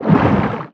Sfx_creature_pinnacarid_push_03.ogg